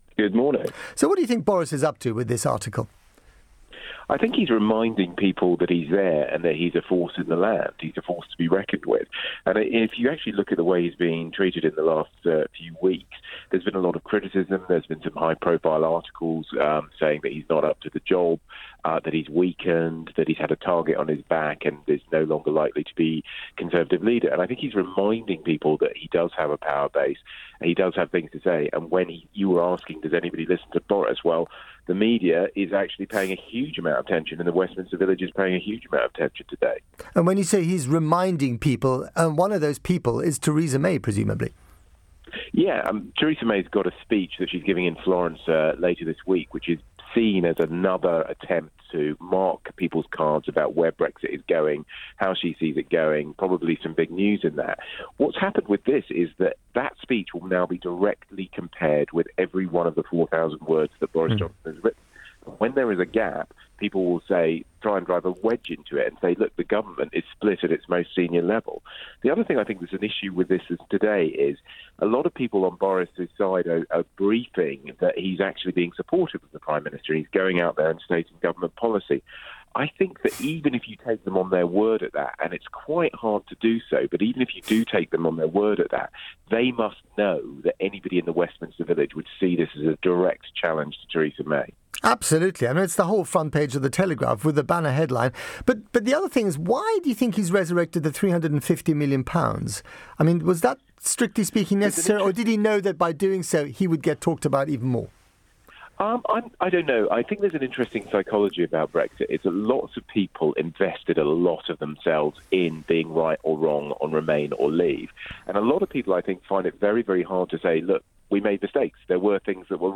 Boris Johnson has written in the Telegraph that Britain will still claw back £350m a week after leaving the EU. Cameron's former Comms Director tells Matt Frei there's still a chance he could become Tory leader and Prime Minister